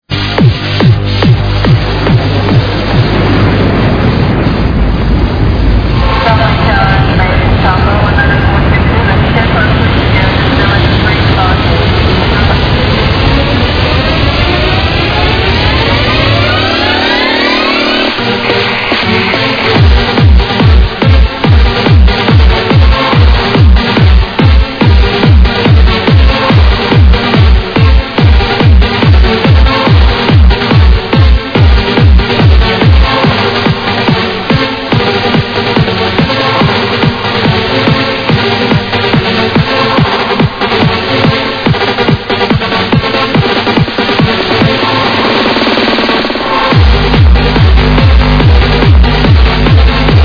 Unknown Trance-ID, I like it!